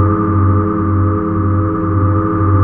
machine2.ogg